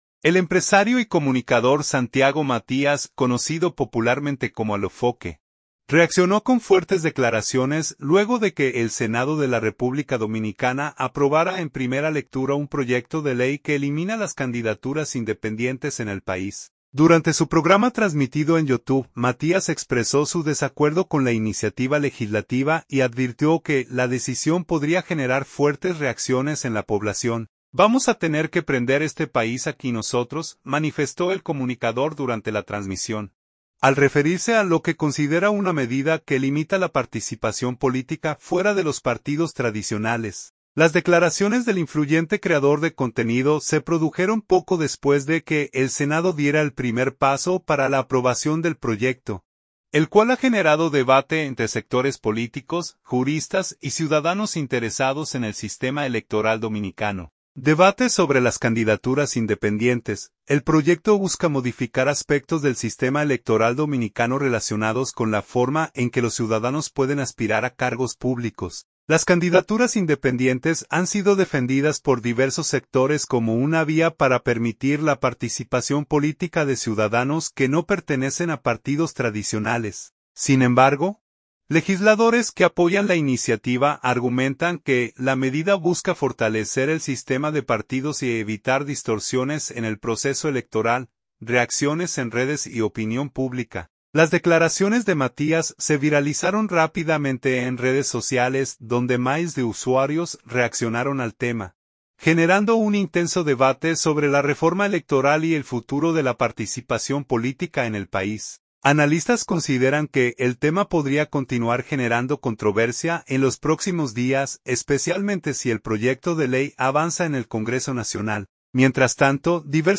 Durante su programa transmitido en YouTube, Matías expresó su desacuerdo con la iniciativa legislativa y advirtió que la decisión podría generar fuertes reacciones en la población.